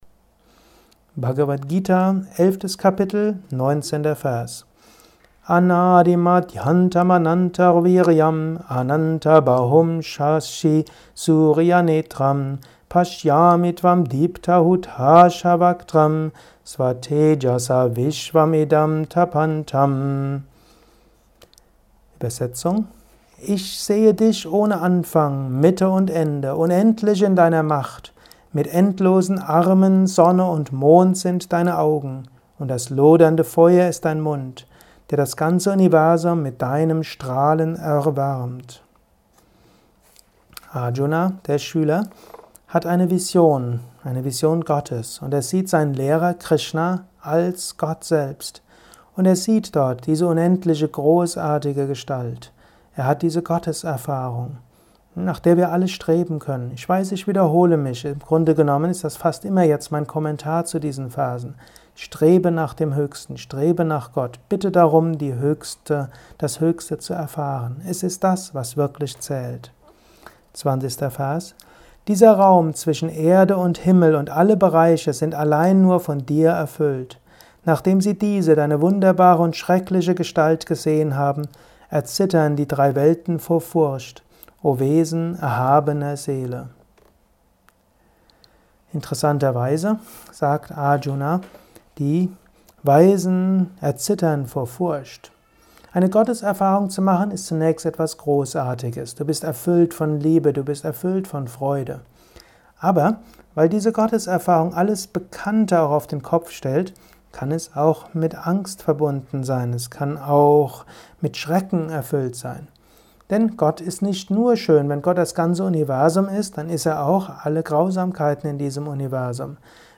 Kurzvorträge
Dies ist ein kurzer Kommentar als Inspiration für den heutigen